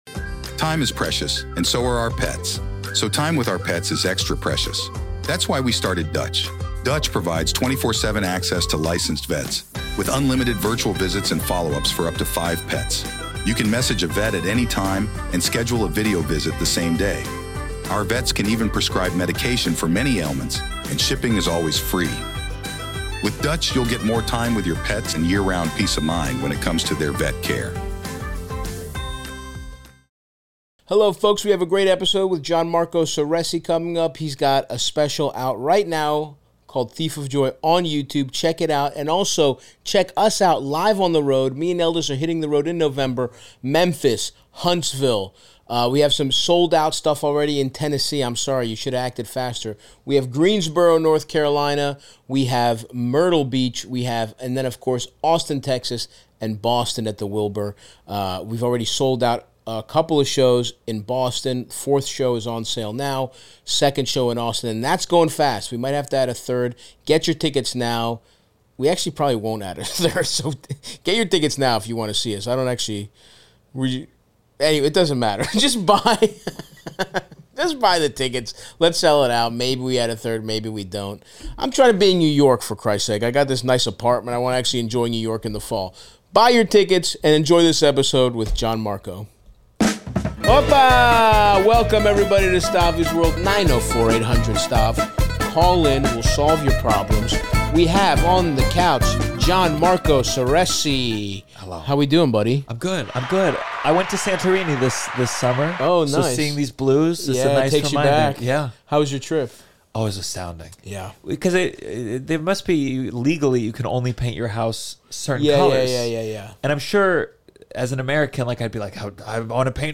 Gianmarco and Stav help callers including a divorced man grappling with the ethics of being a passport bro, and a guy considering skipping his friend's bachelor party to avoid an unhinged guy who will be in attendance.